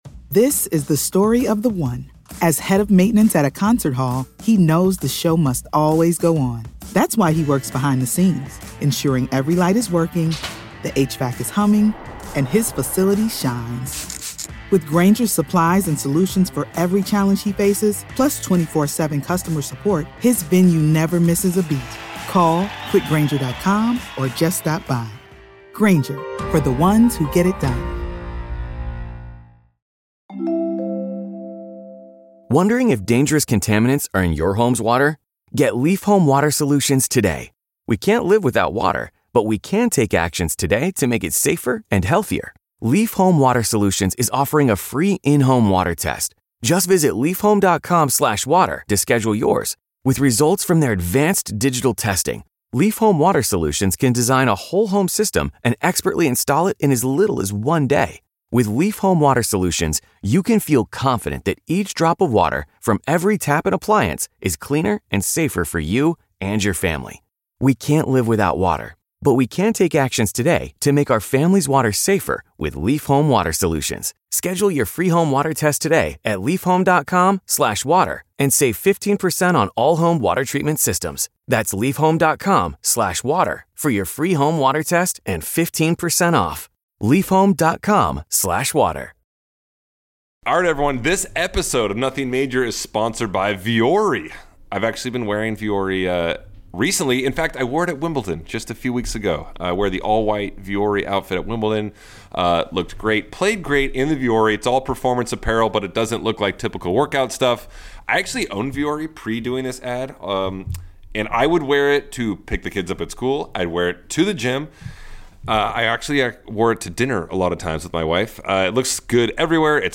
John, Sam and Stevie discuss their own Wimbledon experiences and share stories from the Legends Tournament to Sam's surprising attendance at this year's Champion's Dinner. The boys dip into the Major Mailbag to answer some listener questions and the winner of the 2025 Wimbledon Bracket Challenge joins the boys to celebrate his victory.